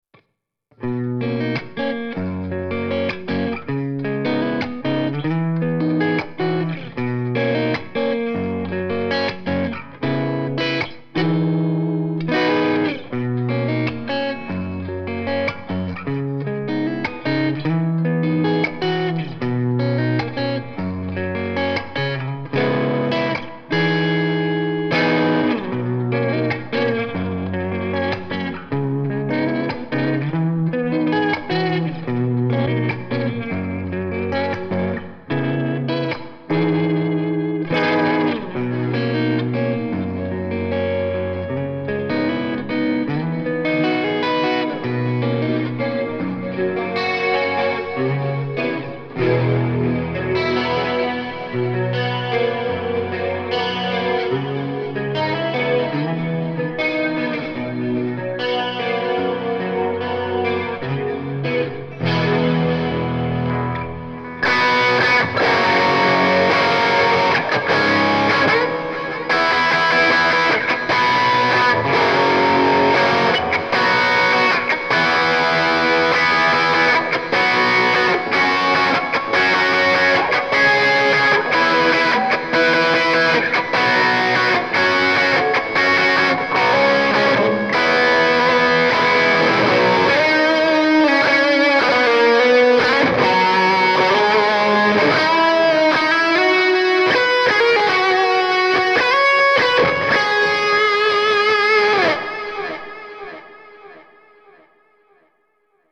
This Amp Clone rig pack is made from an Tone King Imperial MKII preamp.
RAW AUDIO CLIPS ONLY, NO POST-PROCESSING EFFECTS